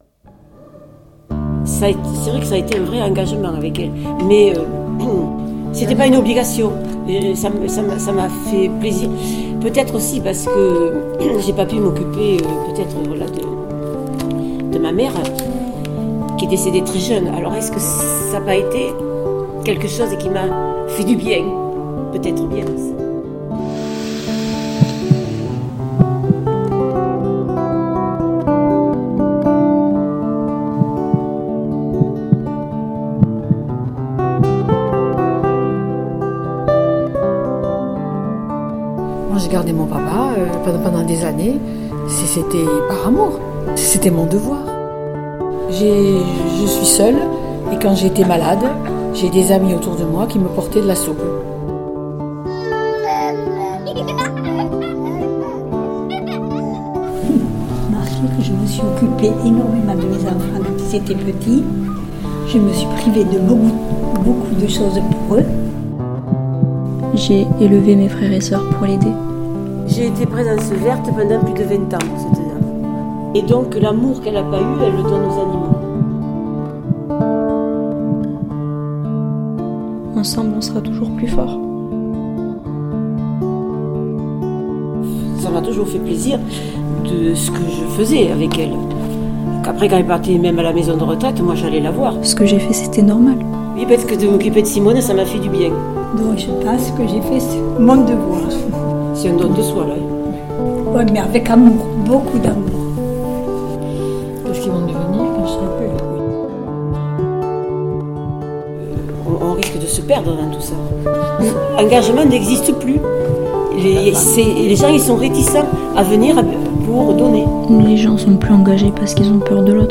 Exposition sonore : Regards croisés d’habitants, de jeunes, de familles, d’usagers des centres sociaux sur l’engagement au féminin.